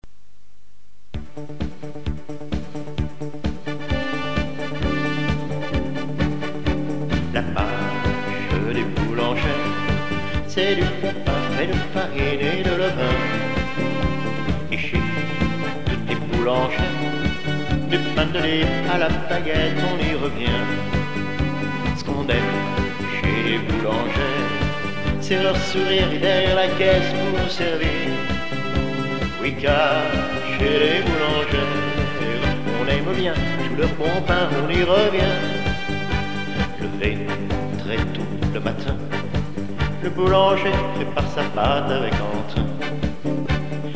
VARIETE FRANCAISE chantée (extraits)
Marche (chantée)